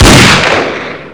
sol_reklam_link sag_reklam_link Warrock Oyun Dosyalar� Ana Sayfa > Sound > Weapons > M500 Dosya Ad� Boyutu Son D�zenleme ..
WR_fire.wav